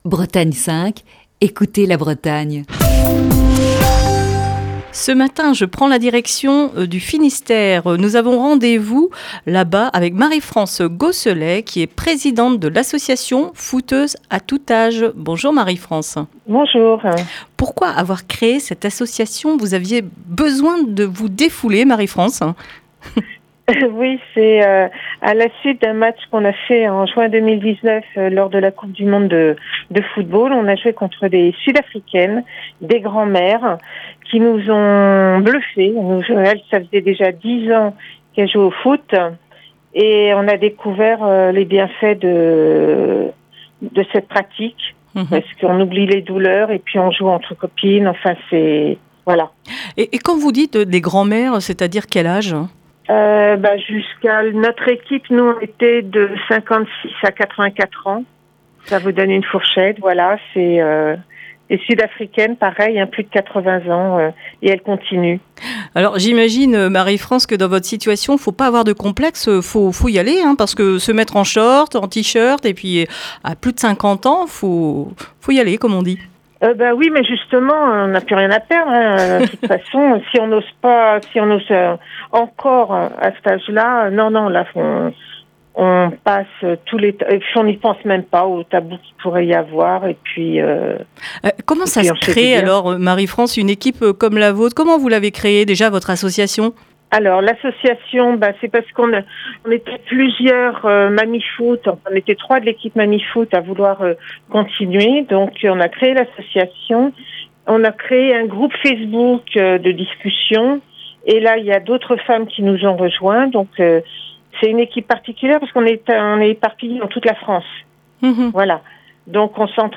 est au téléphone avec